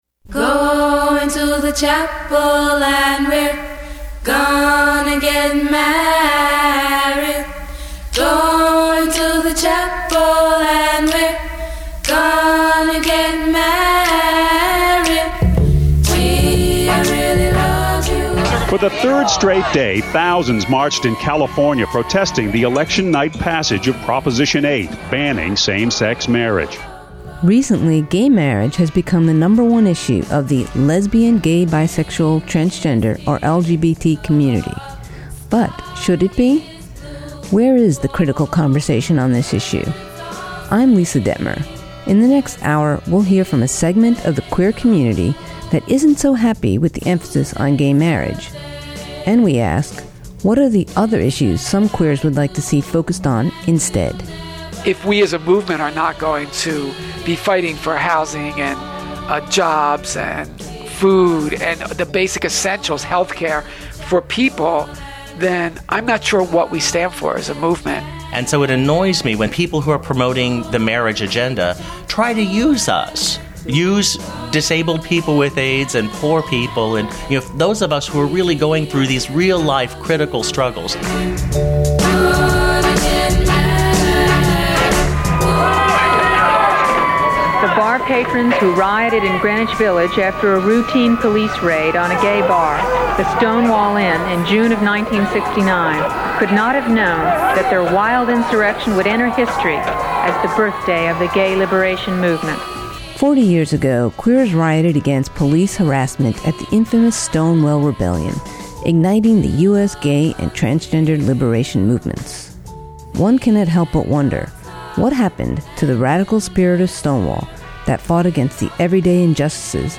public affairs radio documentary
What effect does the funding and emphasis on Same Sex Marriage have on displacing other crucial life and death LGBT issues ? This documentary presents the voices of LGBT scholars and activists seldom heard in the media who provide a cutting edge critique of the "Gay Marriage" movement and the mainstreaming of gay politics and looks at what progressive Queers are working on instead.